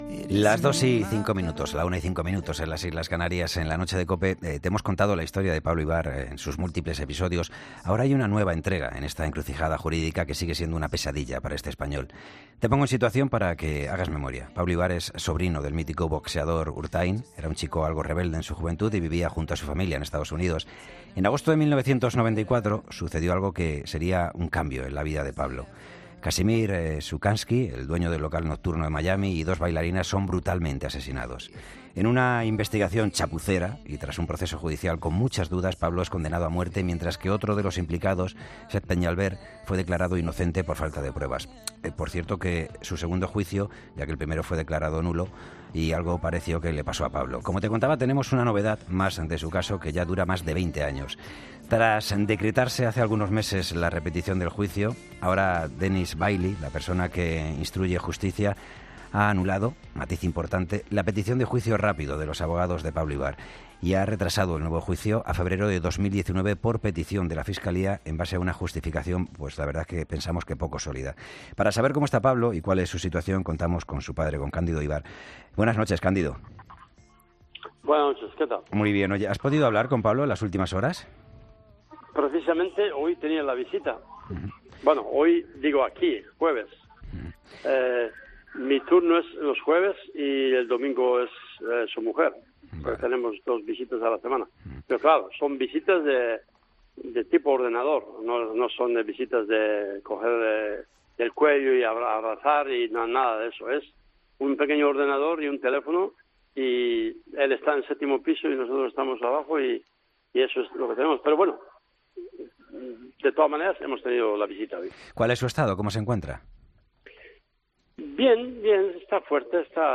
Entrevista 'La Noche'